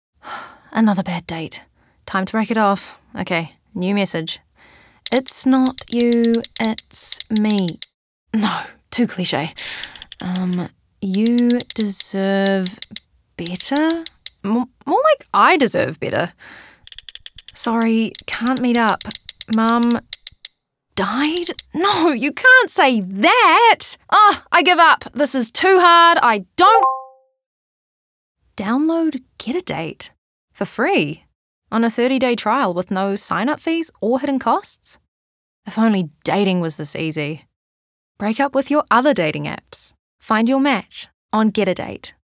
Her voice spans from warm and smooth to friendly and energetic, maintaining a sense of trustworthiness, sophistication, and eloquence that suits a variety of voice work.
conversational